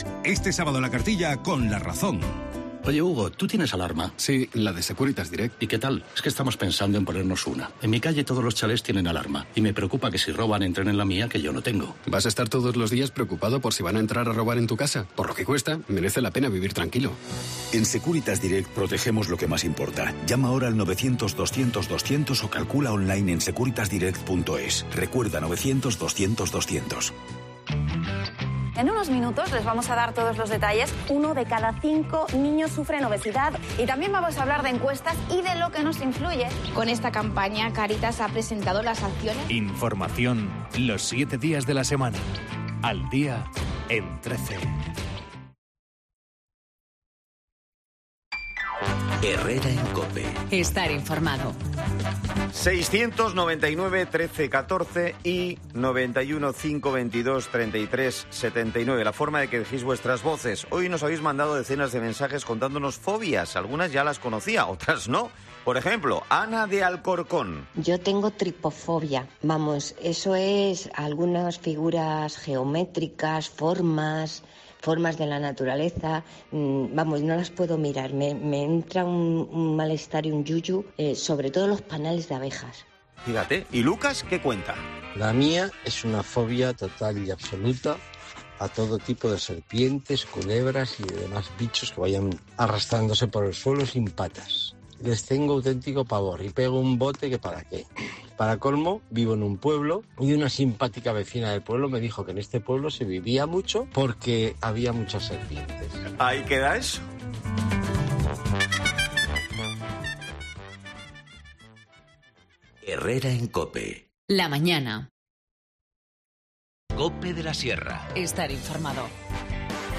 Empleo y formación, las obras de la piscina o los campamentos de verano son algunos de los asuntos de los que hemos charlado con Adan Martínez, concejal de Comunicación, en el espacio Collado Villalba, Capital de la Sierra.